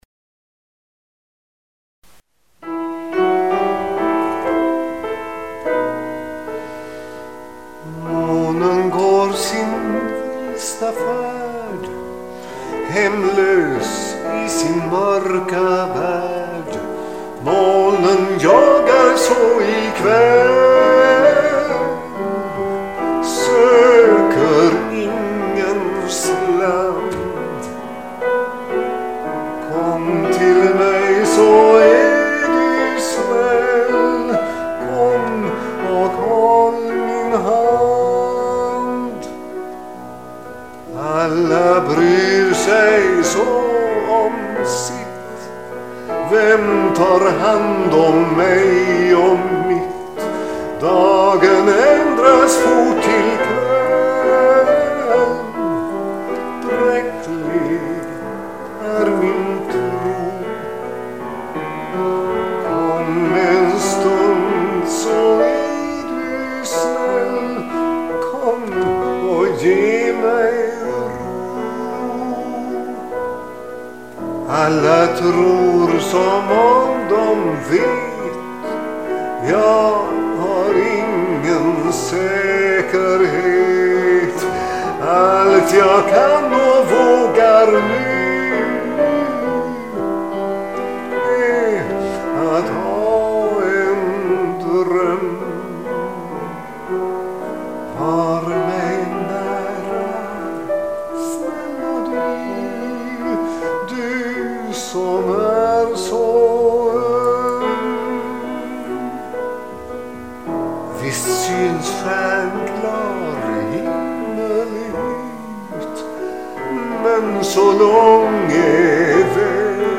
Det rör sig om inspelningar med sång till eget pianoackompanjemang.
Jag ber den som lyssnar notera att alla visorna är tagna ”live”.